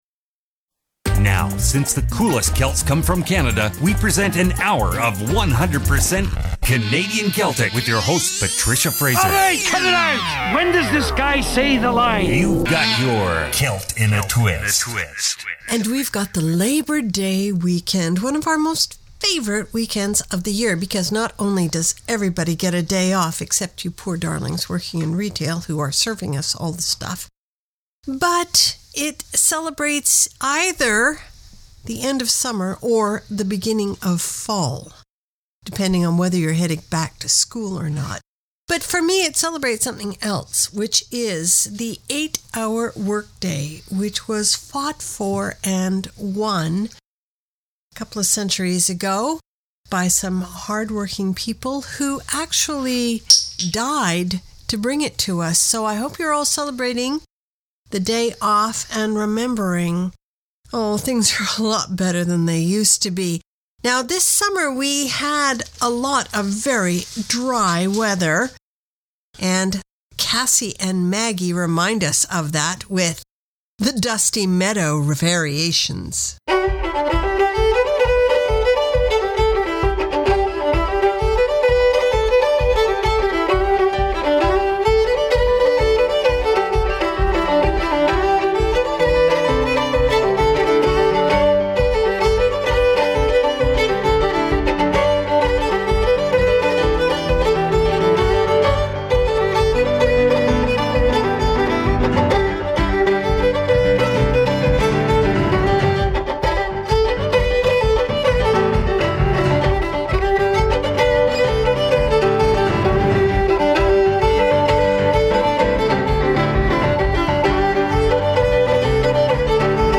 Canada's Contemporary Celtic Radio Hour